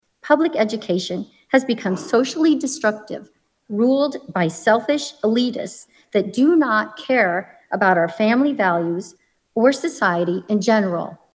Des Moines, Iowa — Parents, educators, school board members, and a couple of students testified at last (Tuesday) night’s public hearing on the governor’s plan to give parents state money to cover private school expenses.